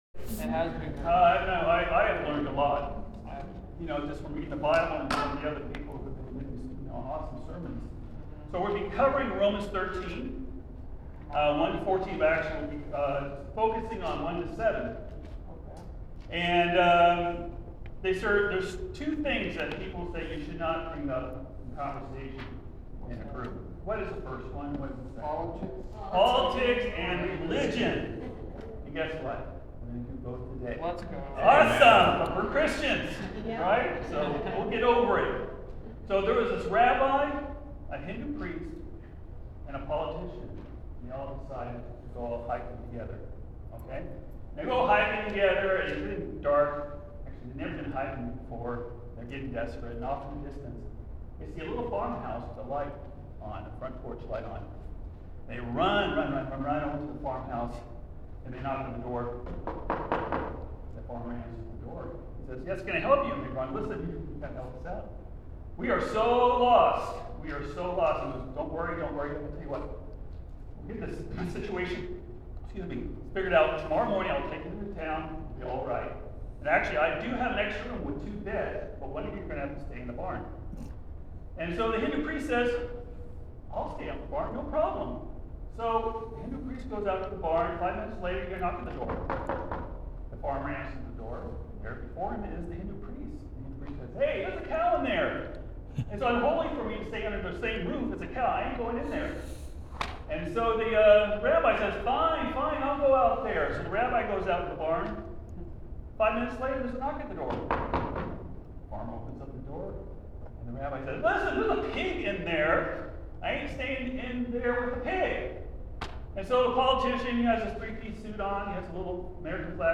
Sermons | Tri-County Church